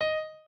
piano6_21.ogg